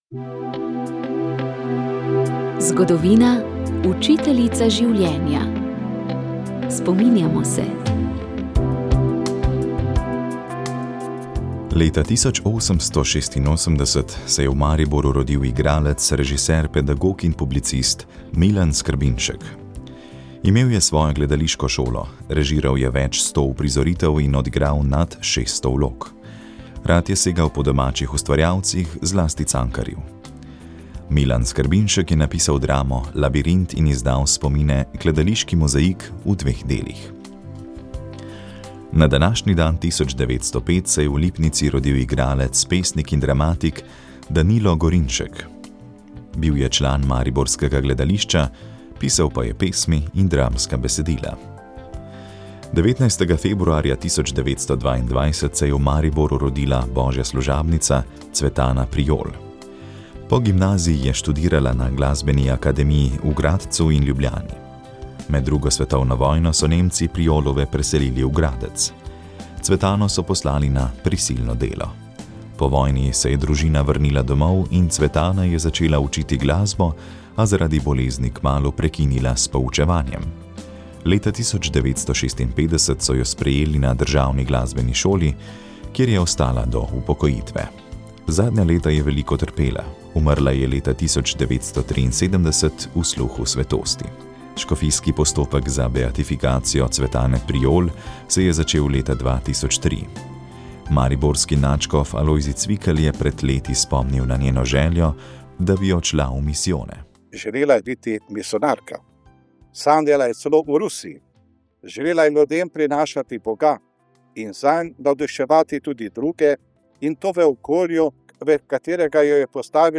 Daroval jo je ljubljanski nadškof Stanislav Zore, ki je po maši ob 11.30 v Galeriji Družina še odprl prodajno razstavo Umetniki za karitas. V oddaji so spregovorili tudi nekateri sodelujoči umetniki!